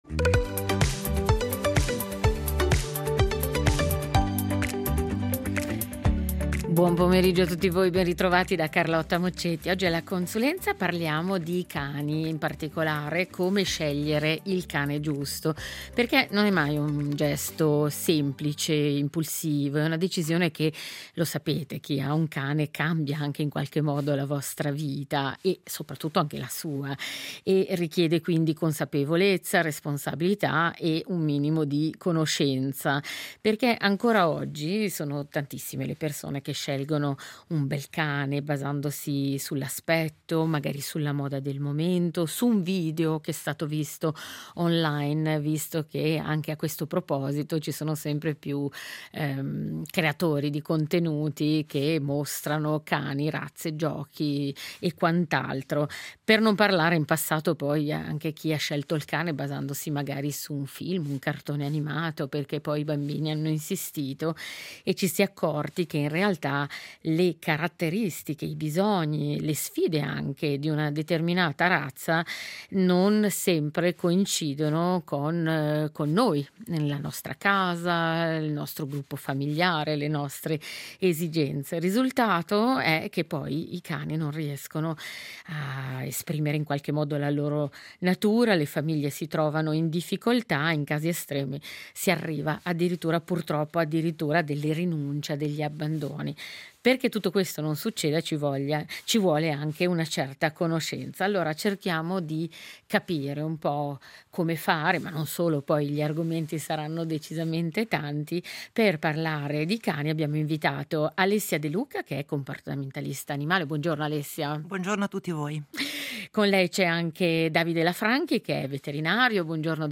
La consulenza